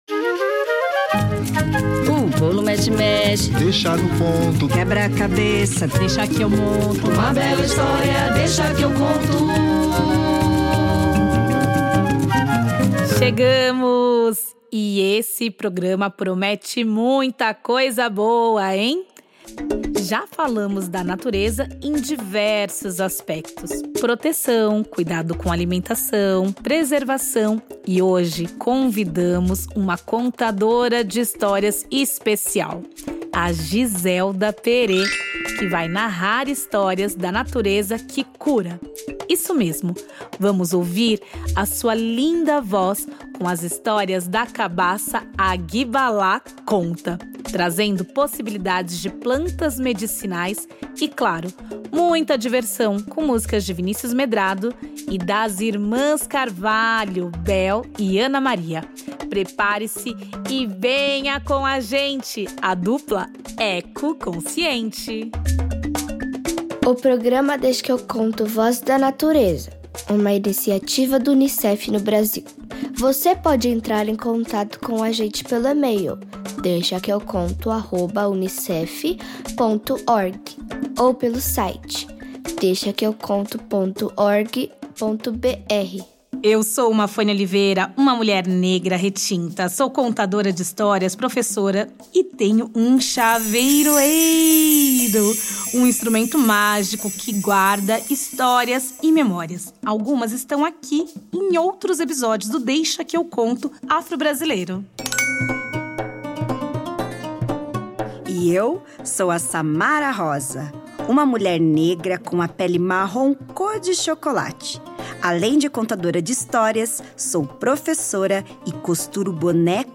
No programa ainda tem muita informação, pois saberemos quais são as plantas mais usadas para substituir o uso do remédio que vende na farmácia no estado do Pará. Tem música boa também!